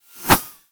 bullet_flyby_12.wav